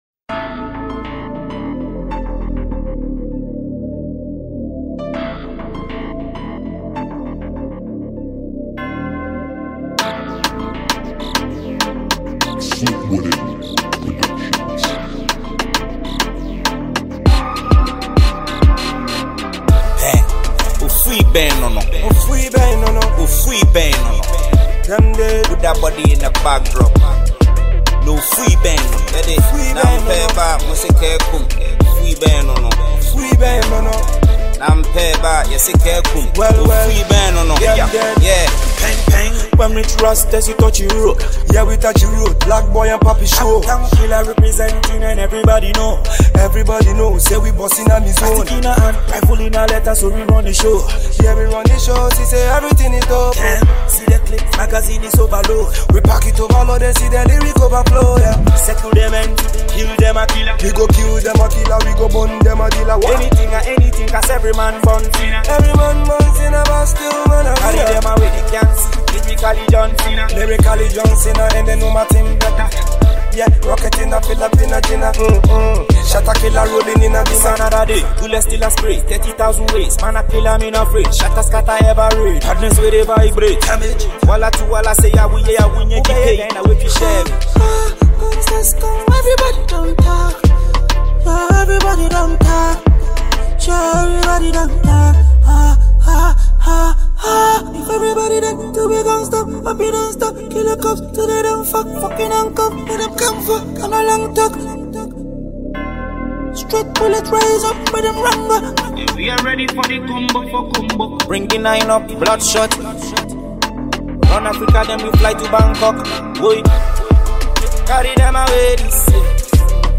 Ghana Music
Ghanaian foremost rapper